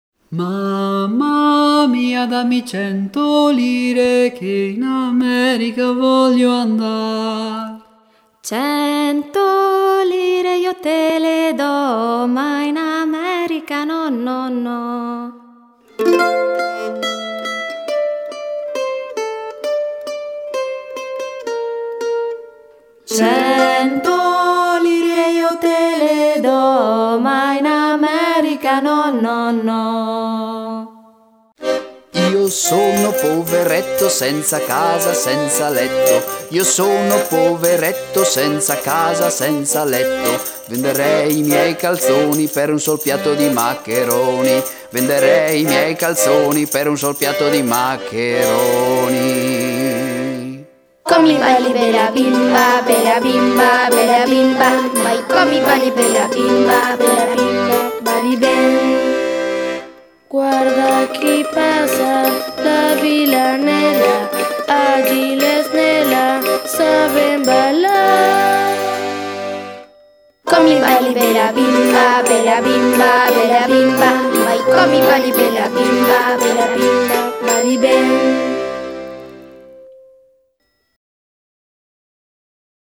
Canciones italianas
Parabailar
La tarantella (tarántula en español) es una danza tradicional de Italia muy animada, en la cual se dan saltitos imitando el acto de espantar arañas.